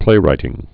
(plārī-tĭng)